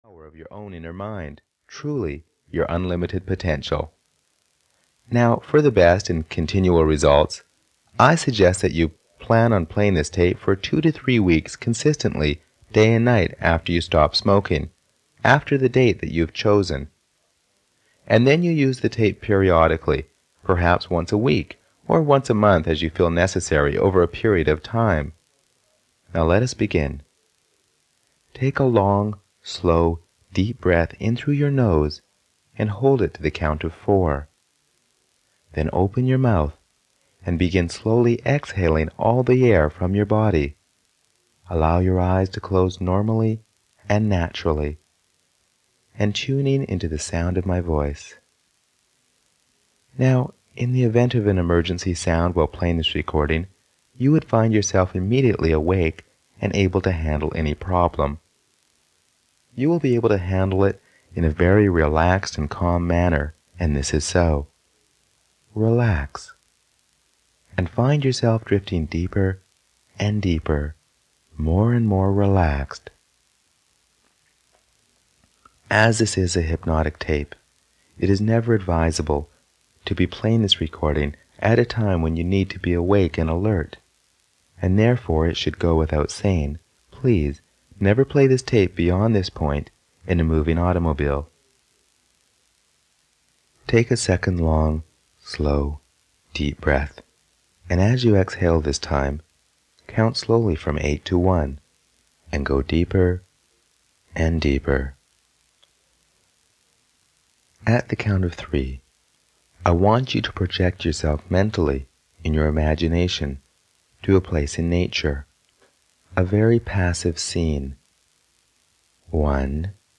Stop Smoking (EN) audiokniha
Ukázka z knihy